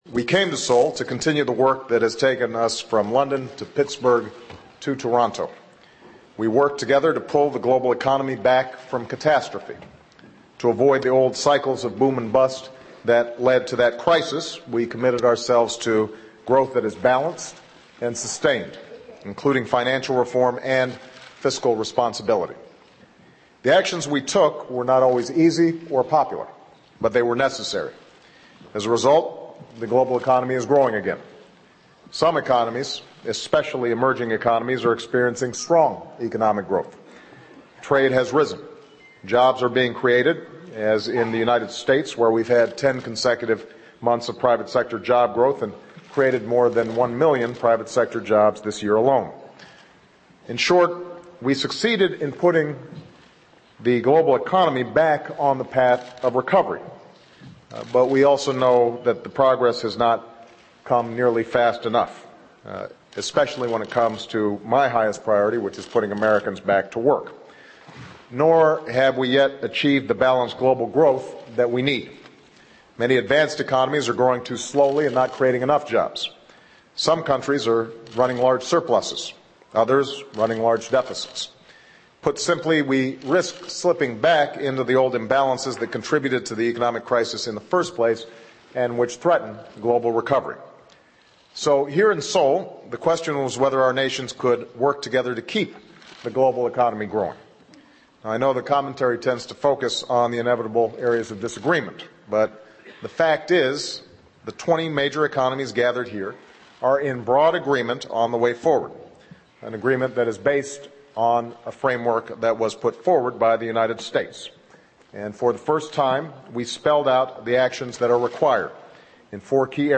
Obama Statement G20